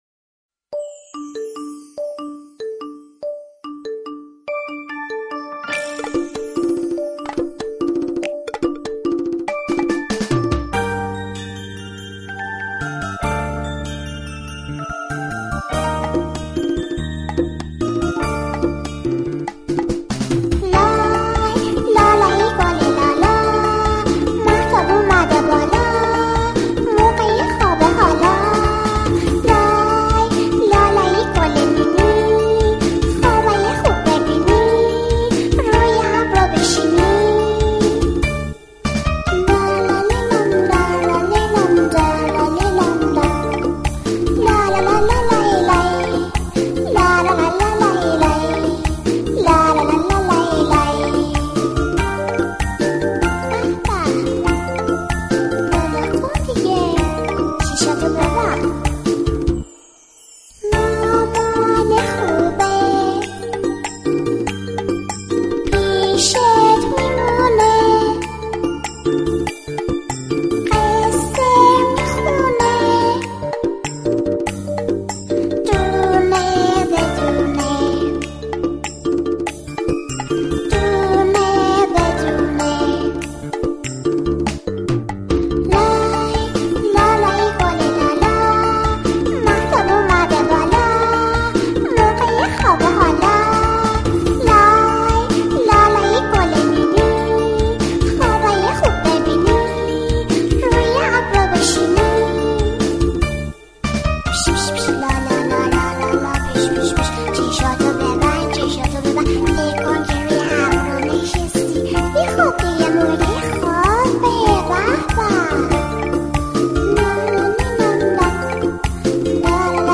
لالایی کودکانه